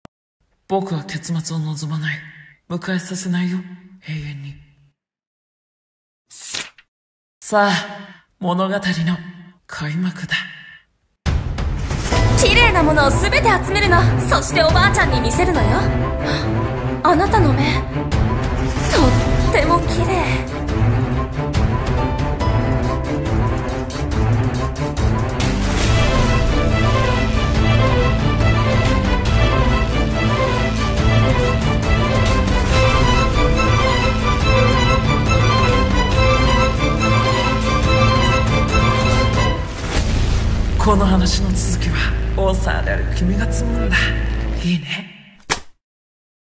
【CM風声劇】愛憎ゆえの傲慢